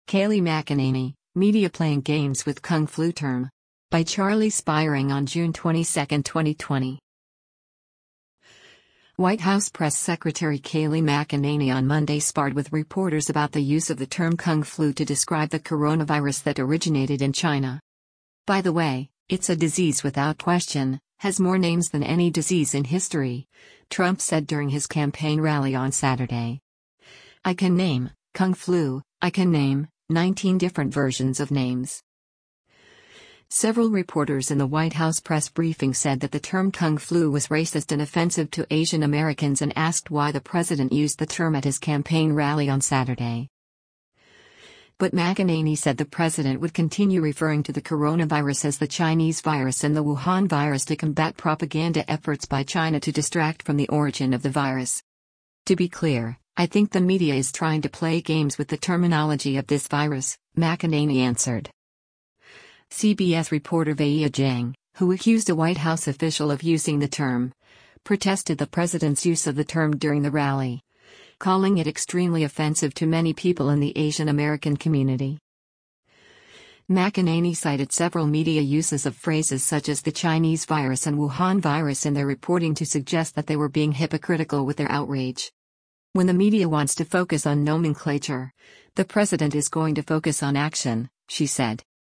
White House Press Secretary Kayleigh McEnany on Monday sparred with reporters about the use of the term “Kung Flu” to describe the coronavirus that originated in China.
Several reporters in the White House press briefing said that the term “Kung Flu” was “racist” and “offensive” to Asian Americans and asked why the president used the term at his campaign rally on Saturday.